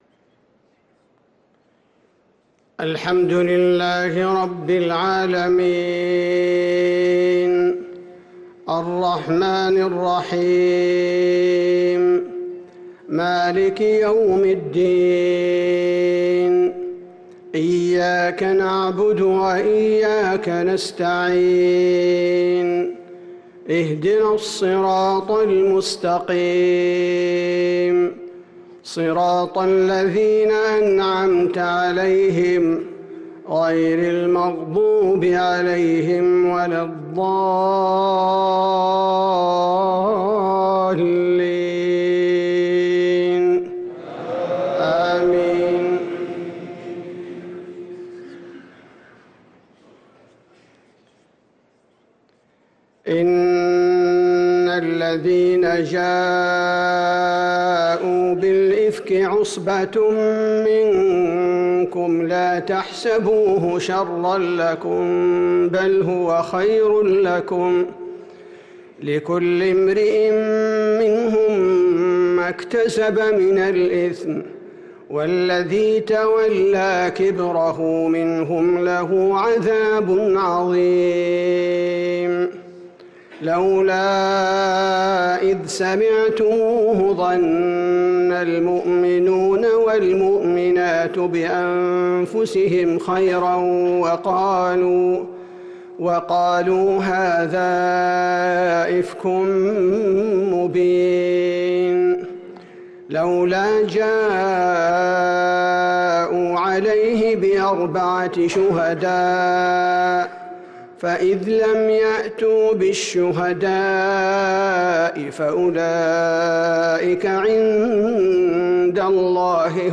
صلاة العشاء للقارئ عبدالباري الثبيتي 19 شعبان 1445 هـ